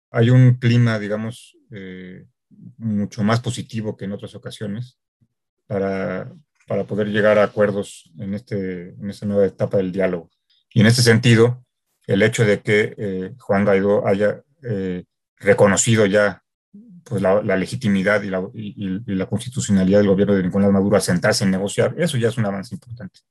En conferencia de medios a distancia